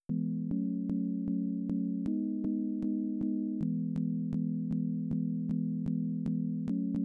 It sounds perfect. We need to test latency, but as for sound quality, it’s great.
adlib_fixed1.flac